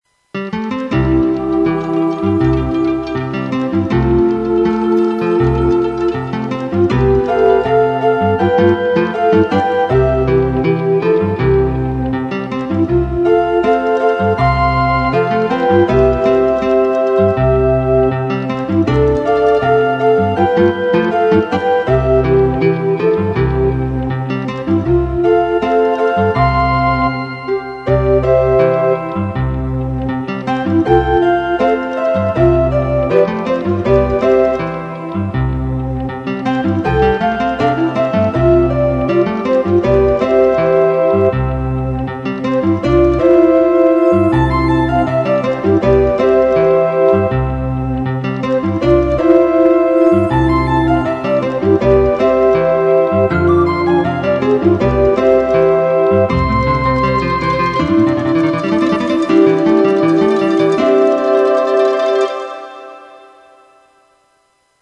HERO & TRIVIA Kanon endet mit ... maybe tomorrow !
04tlstiwanttoseeyouagainorch.mp3